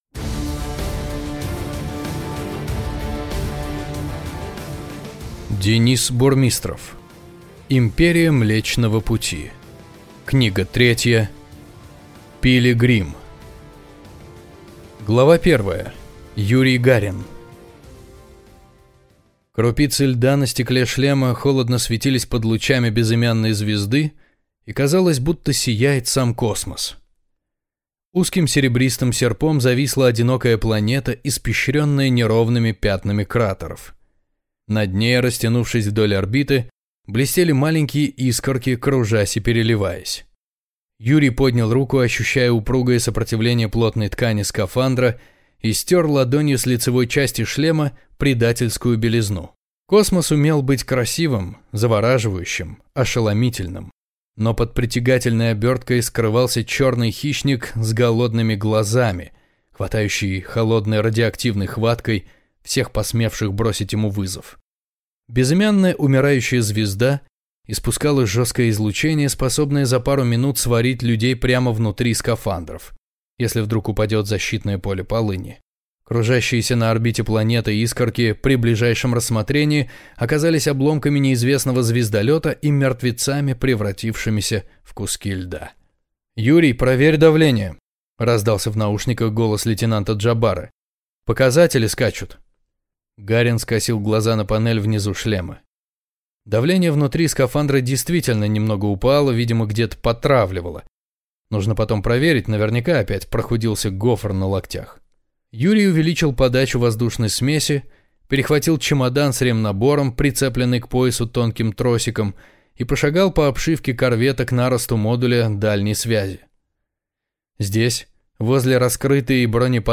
Аудиокнига Империя Млечного пути. Книга 3. Пилигрим | Библиотека аудиокниг